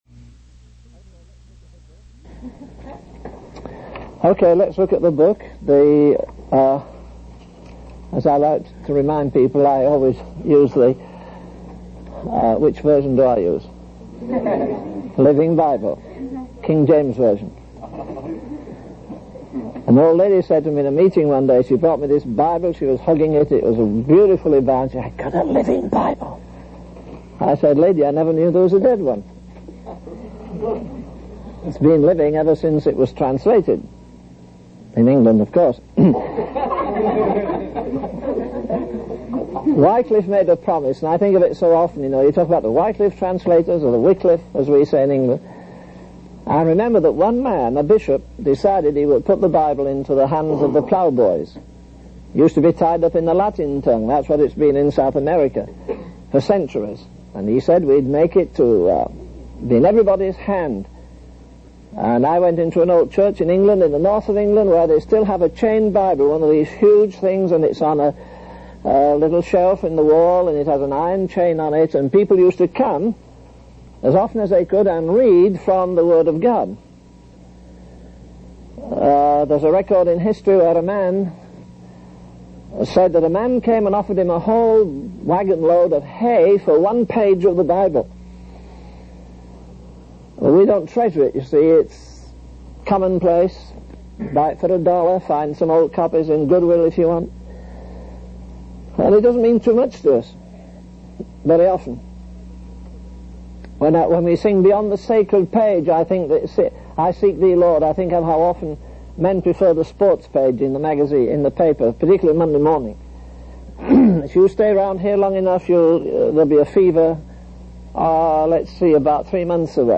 The preacher also highlights the significance of showing reverence and respect in our worship, comparing it to how we would behave in the presence of important figures like the Queen of England or the President of the United States. Overall, the sermon encourages listeners to prioritize their love for God and approach worship with sincerity and reverence.